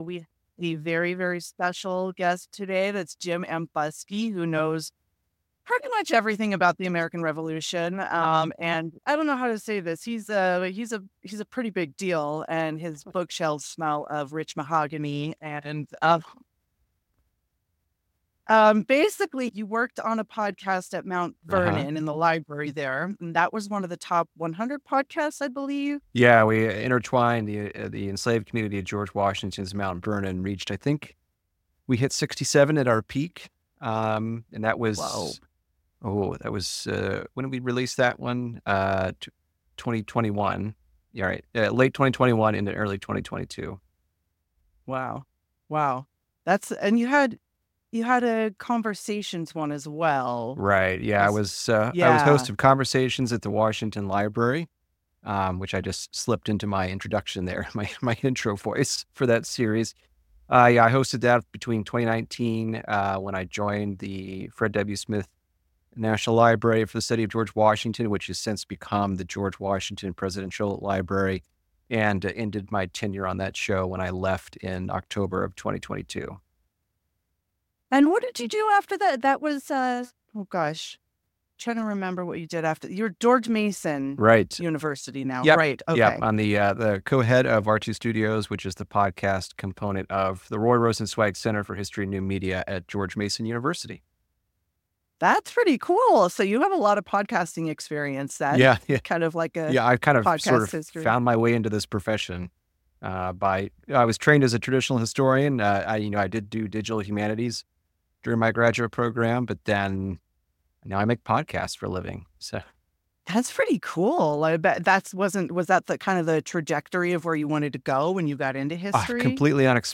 Why Wars Happened - Interview Series